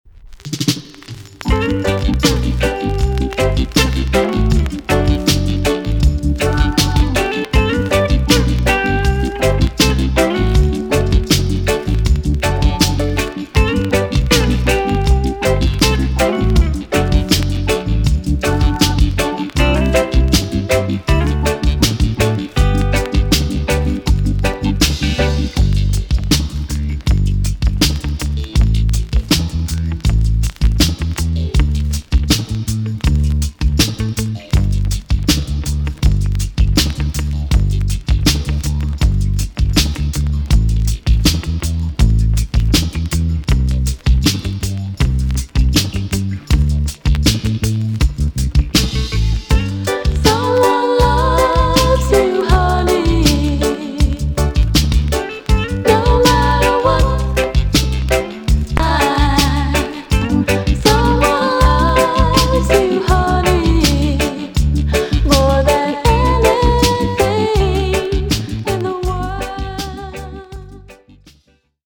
B.SIDE Version
VG+ 少し軽いチリノイズが入りますが良好です。